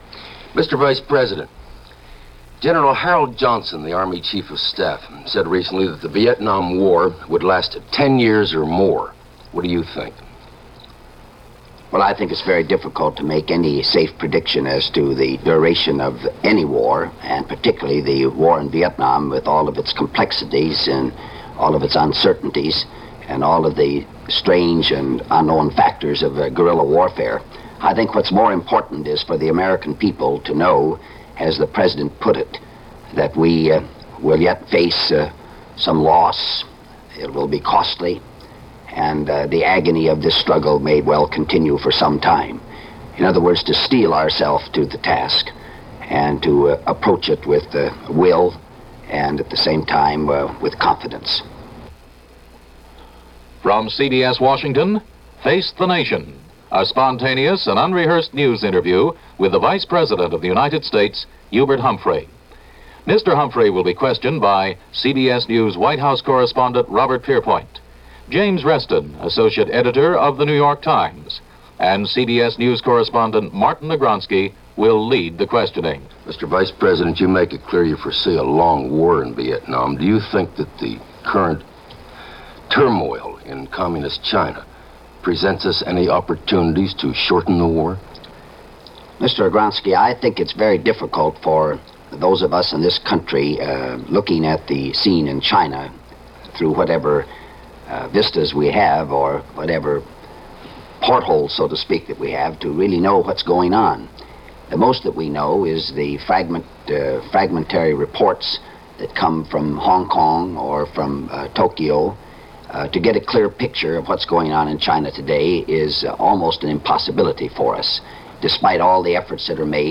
This episode of CBS Radio/TV’s Face The Nation program featured no less than vice-President Hubert Humphrey, selling the White House position on the war to a panel of CBS correspondents and hopefully to the American people: Face The Nation – February 22, 1967 with vice-President Hubert Humphrey: MARTIN AGRONSKY: MR. Vice President, you make it clear you foresee a long war in Vietnam.